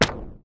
ZAP_Subtle_02_mono.wav